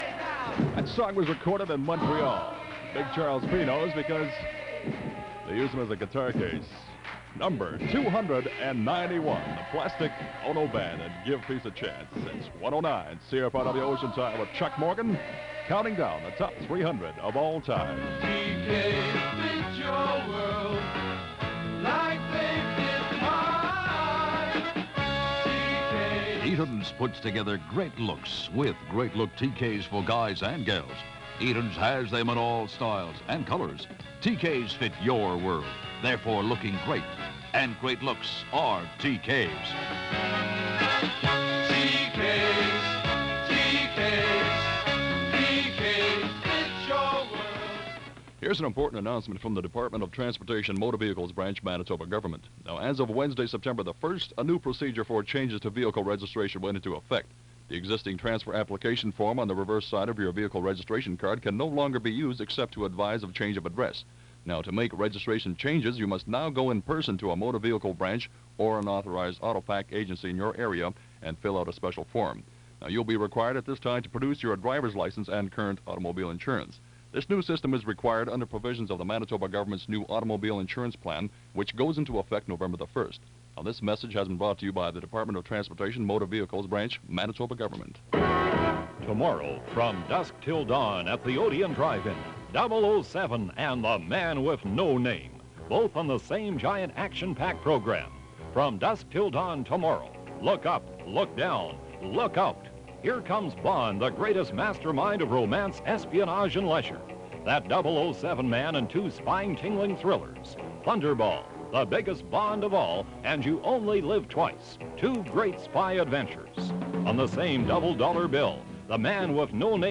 Winnipeg Radio in 1971